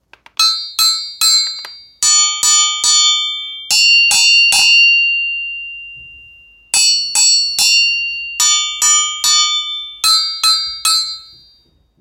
ギニア製鉄ベル ドゥンドゥン用 3点セット アフリカンベル （p505-17） - アフリカ雑貨店 アフロモード
ギニア製のデュンデュン用鉄ベル3点セットです。
説明 この楽器のサンプル音 原産国 ギニア 材質 鉄 サイズ 幅：6.5-6-5cm 高さ：17-16-14cm 重量 1.0g コメント 鉄スティック3本付。